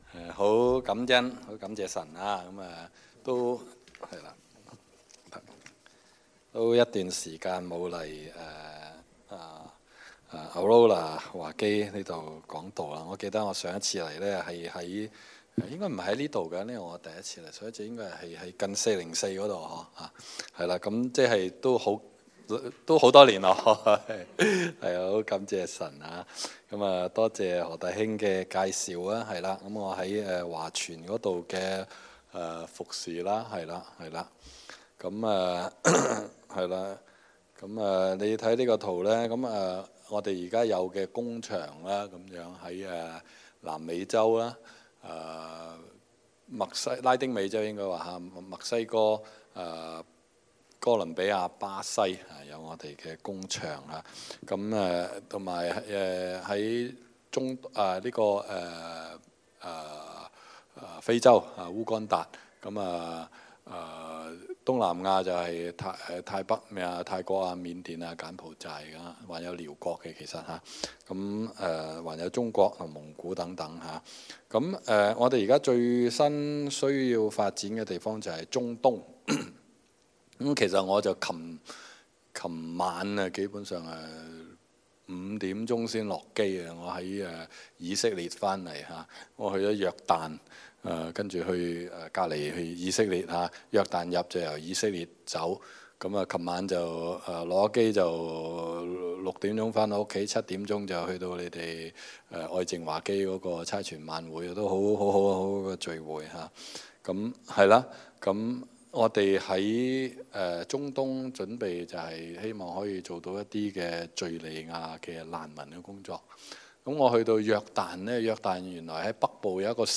求信心，達使命 經文: 使徒行傳 3：1-16 講員